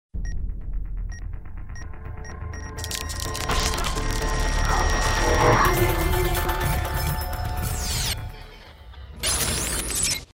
片头曲